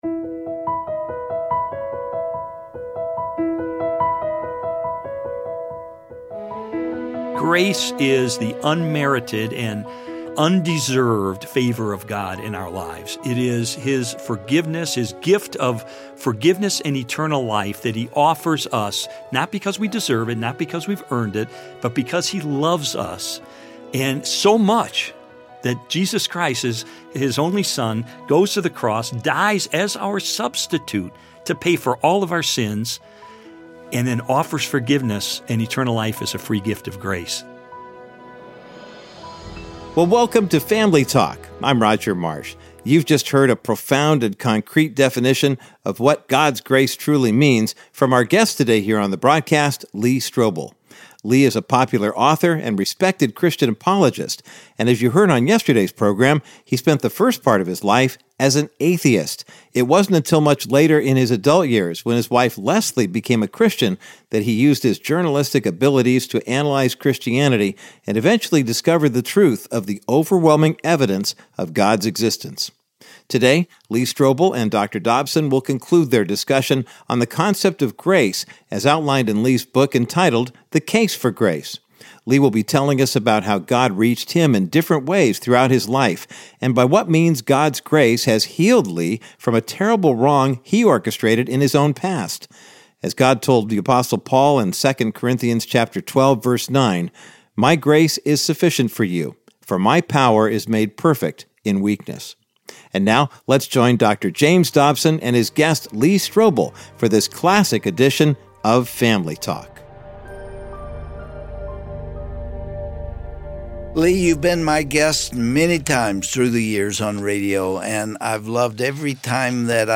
On today’s edition of Family Talk, Dr. James Dobson and Lee Strobel discuss his book, The Case for Grace, pointing out that Christianity is the only faith that offers this unmerited gift of God's grace.
Host Dr. James Dobson